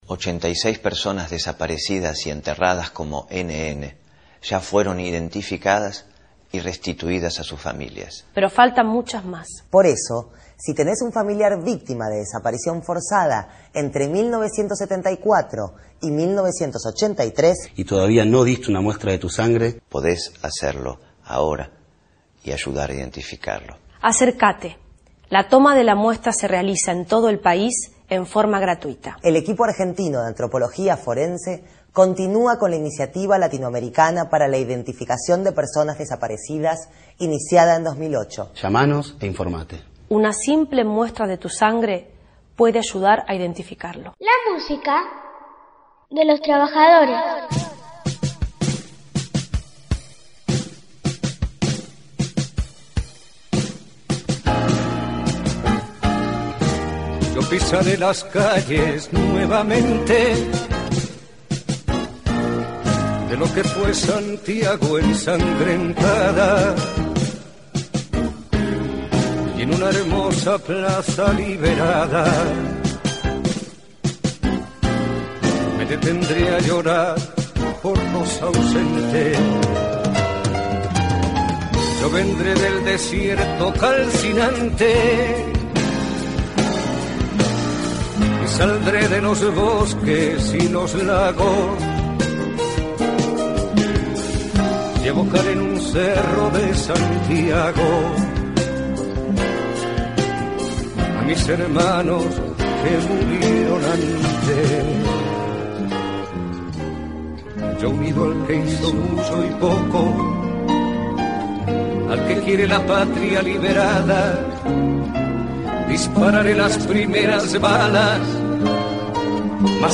Decima tercer emisi�n del programa de Radio de la CTA Pcia. de Bs. As. "De los Trabajadores"